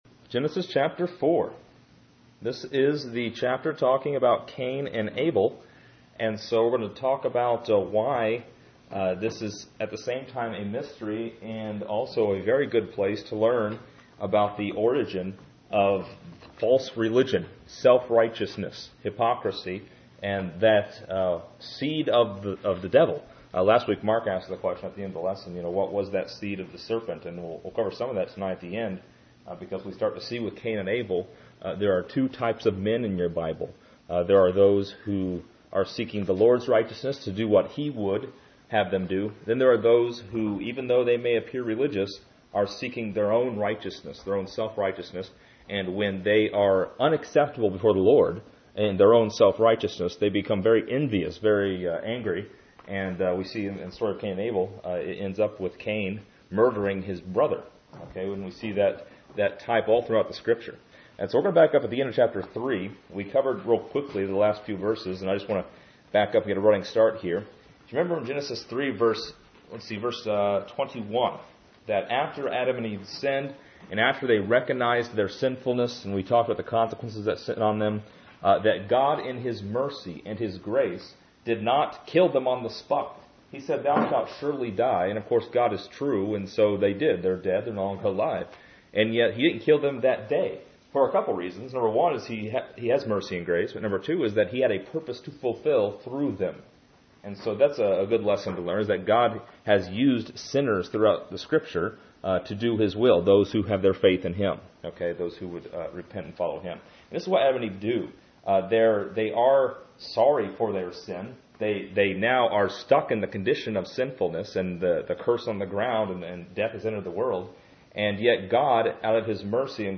This lesson is part 12 in a verse by verse study through Genesis titled: Cain and Abel.